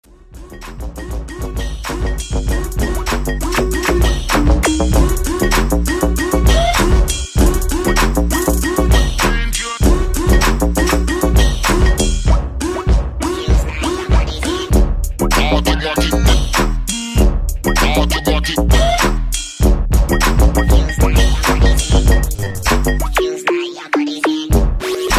Elektronisk musik